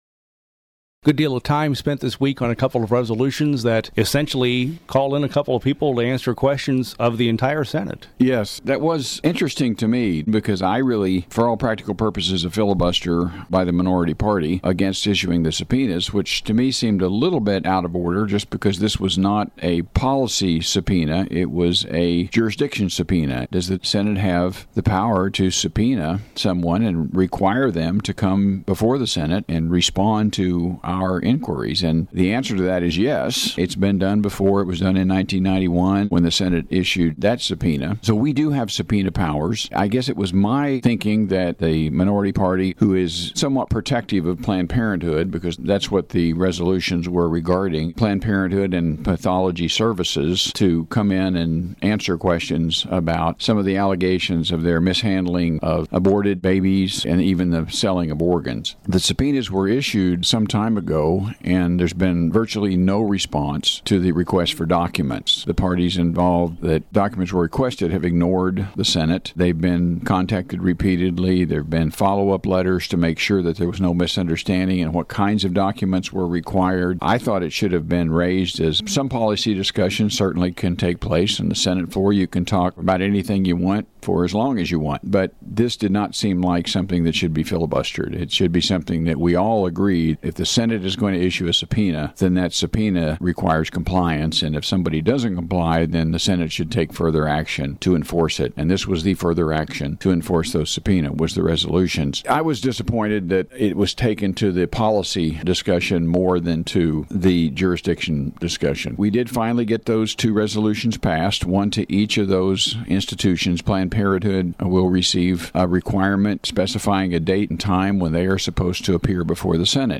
The audio below is a full-length interview with Sen. Emery — also available as a podcast — for the week of April 11, 2016.